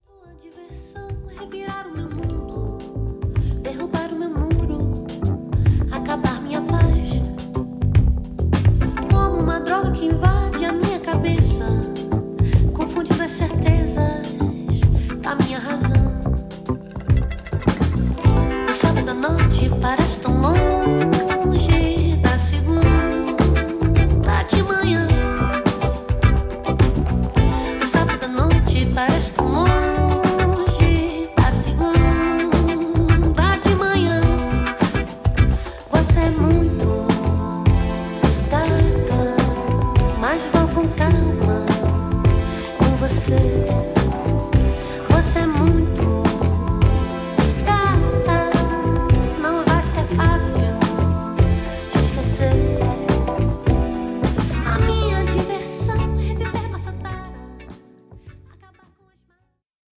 どちらもバンド・サウンドの緩やかなグルーヴも相まってリラックスした仕上がり